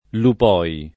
[ lup 0 i ]